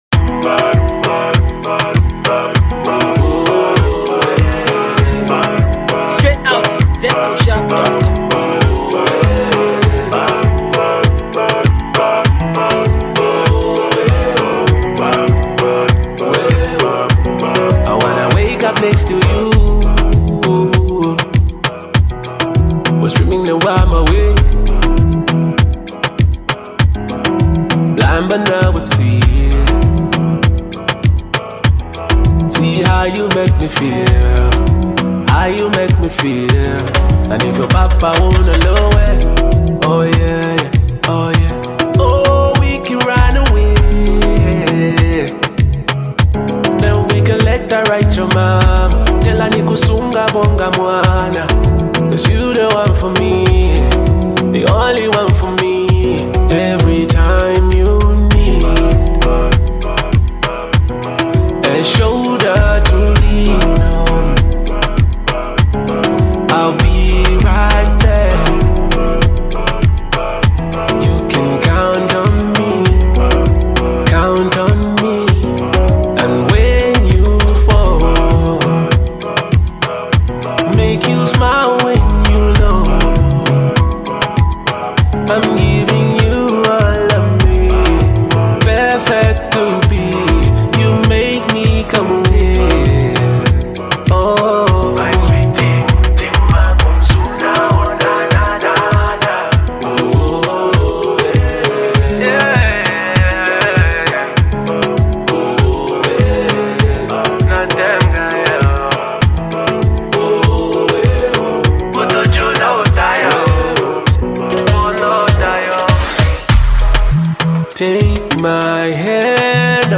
RnB
love song